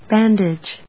音節ban・dage 発音記号・読み方
/bˈændɪdʒ(米国英語)/